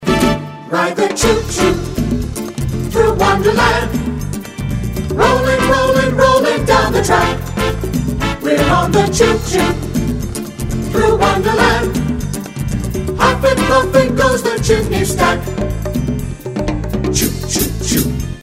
Song Samples: